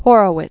(hôrə-wĭts, hŏr-), Vladimir 1903-1989.